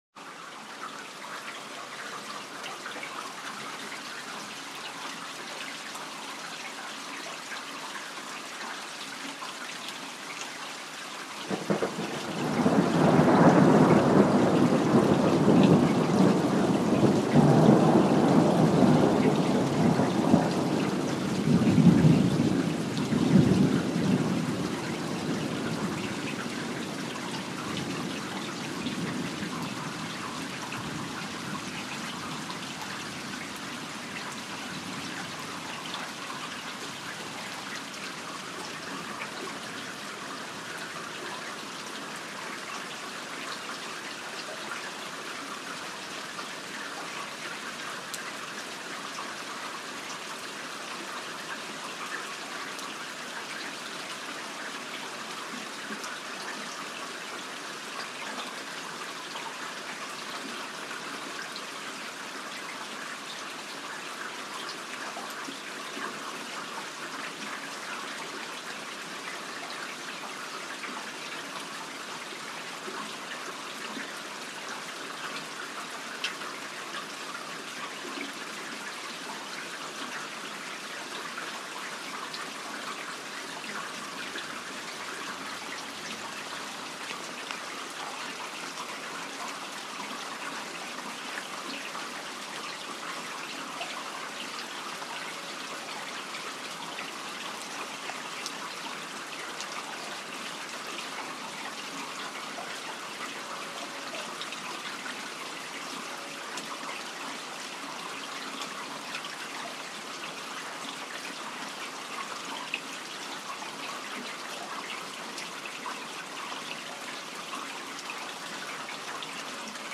Ich habe für euch das Geräusch vom REGEN aufgenommen!/ I registered for you the sound of the RAIN/Ho registrato per voi il suono della PIOGGIA
regen.mp3